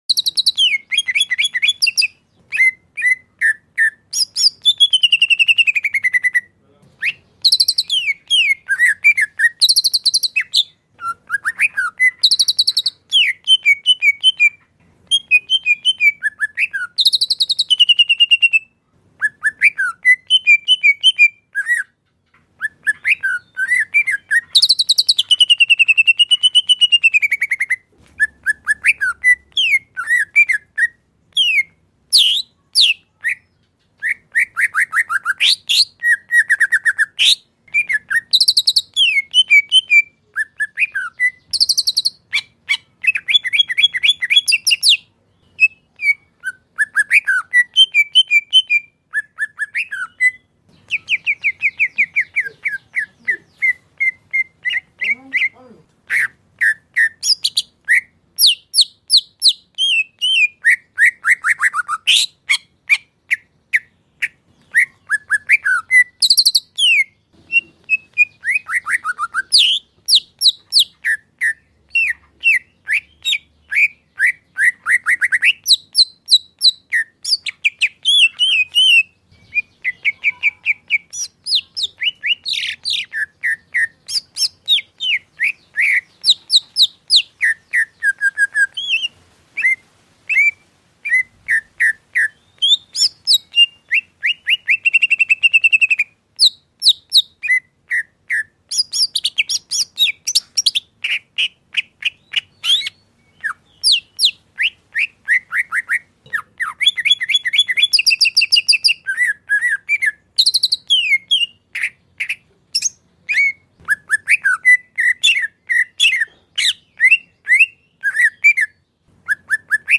Suara Burung Cucak Ijo (Durasi Panjang)
Kategori: Suara burung
Keterangan: Download Suara Burung Cucak Ijo MP3 Durasi Panjang, Ampuh Suara Burung Cucak Ijo Gacor Isian Mewah, Bikin Ijo Mana Saja Cepat Jamtrok!
suara-burung-cucak-ijo-durasi-panjang-id-www_tiengdong_com.mp3